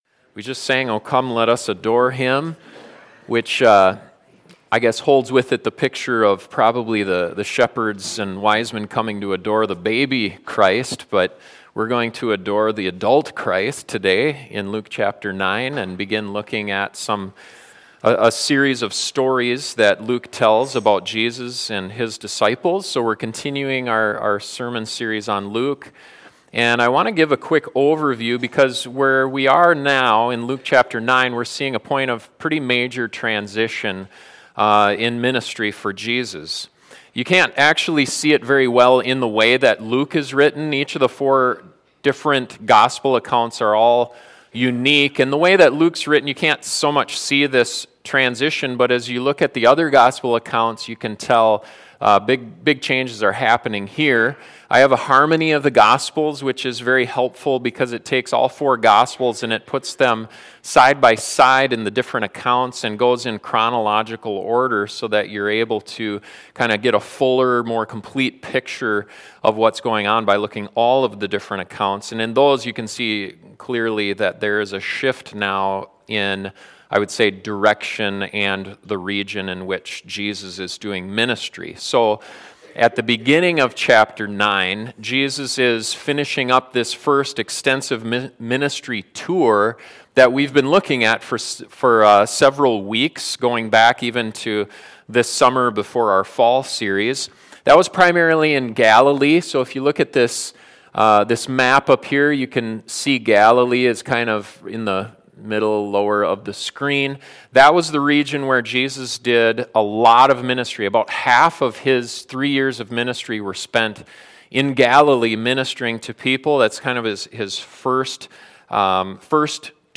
This sermon looks at what Jesus was teaching his disciples in those situations.